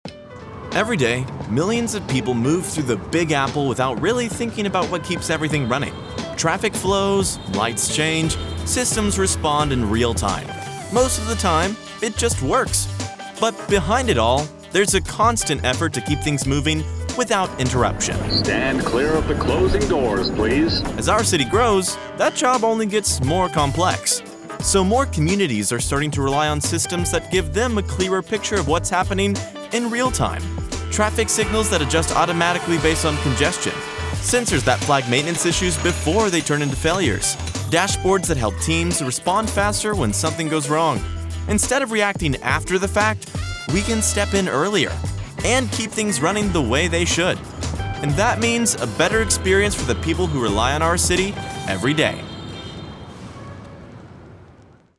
Youthful, Gen Z, energetic male voice with a natural, conversational tone.
Radio Commercials
Carl'S Jr Energetic Radio Spot
Words that describe my voice are Conversational, Young, Energetic.